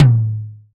DX tom medium.wav